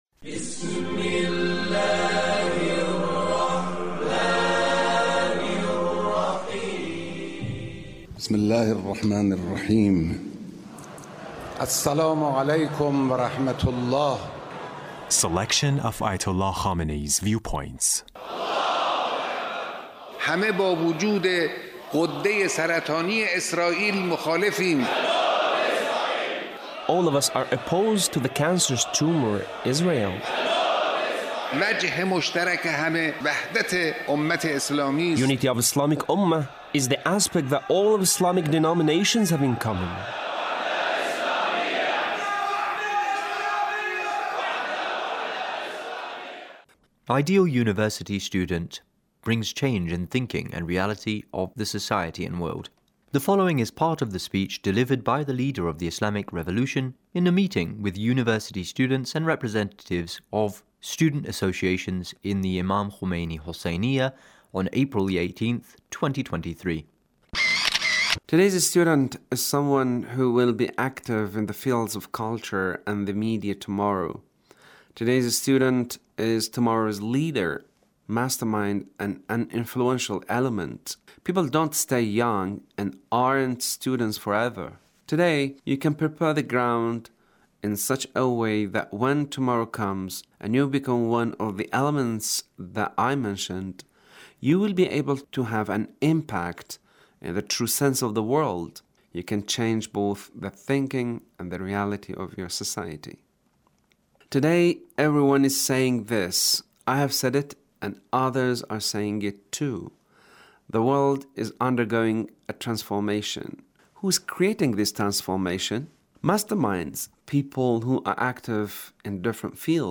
Leader's Speech (1711)
Leader's Speech with University Student